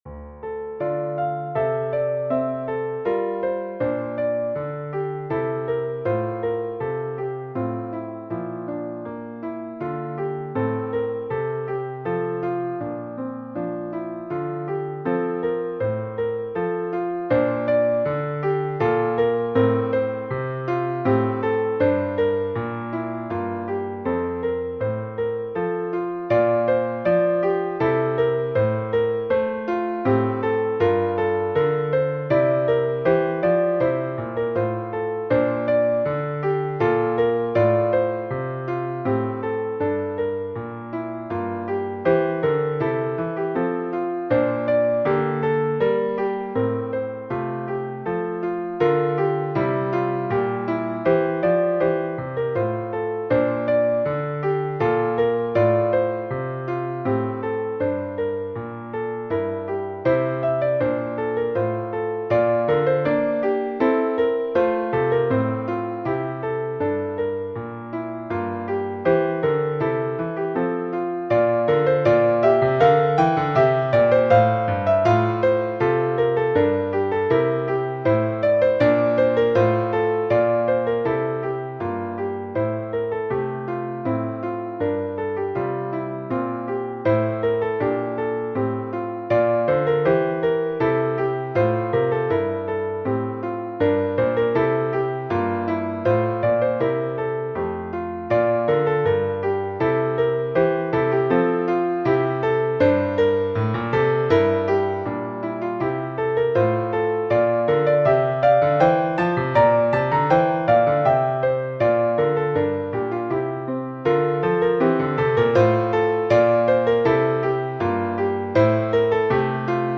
a gentle, lyrical piano solo in the romantic tradition
Modern Classical, Romantic Period